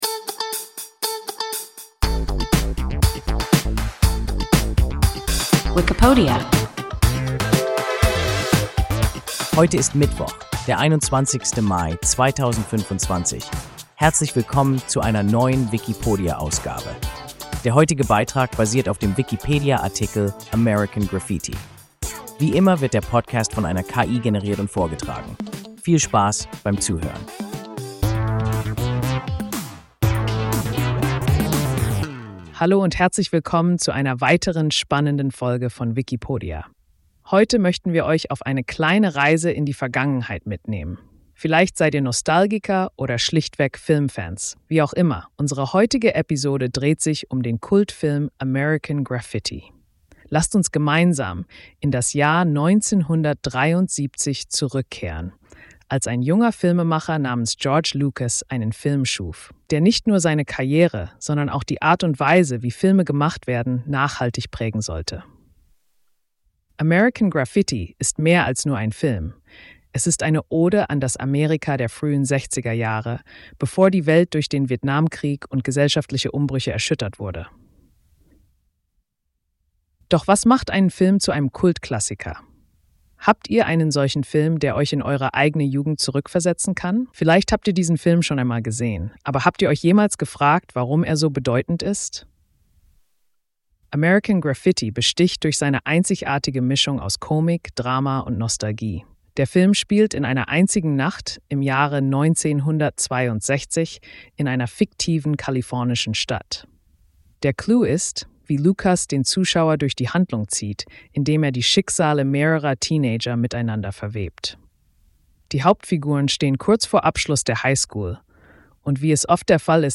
American Graffiti – WIKIPODIA – ein KI Podcast